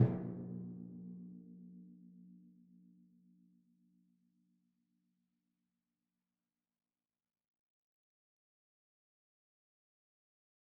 Timpani2_Hit_v3_rr1_Sum.mp3